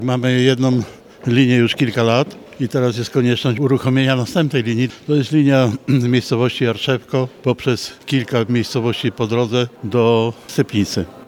Połączenie będzie funkcjonowało w dniu powszednie, podobnie jak linia w gminie Stepnica. O szczegółach Andrzej Wyganowski, burmistrz gminy i miasta Stepnica